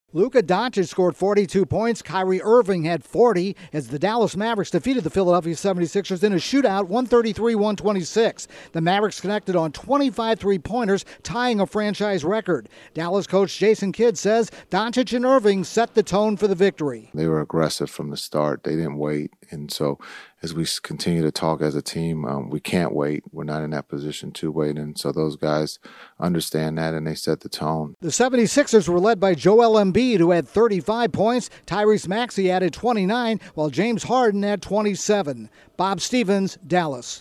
The Mavericks win a track meet against the 76ers. Correspondent